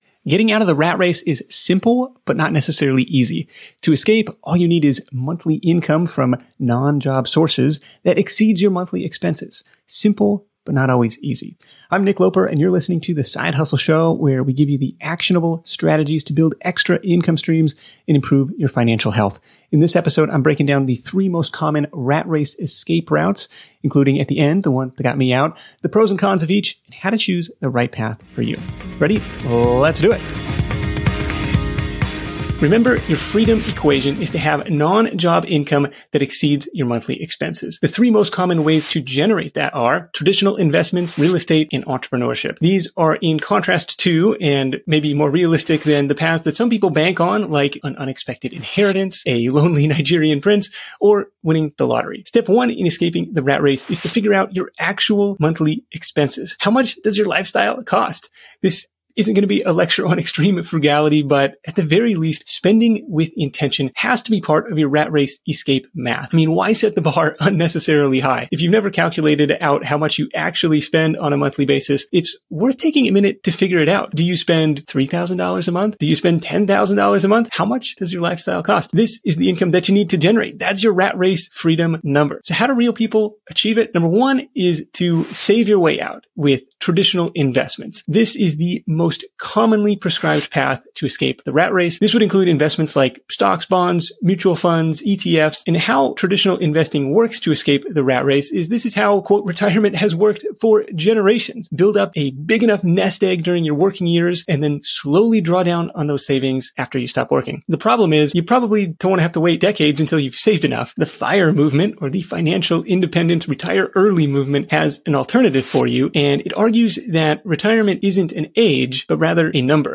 (This was originally recorded for YouTube, which explains the faster-than-normal pacing and some video sound effects.)